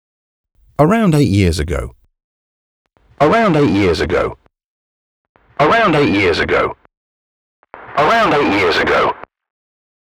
The “Chow Centaur” distortion effect on “neural” is very good emulation of analogue if the “Gain” knob is turned above 0.8
A double dose: “traditional” then “neural” is very walkie-talkie …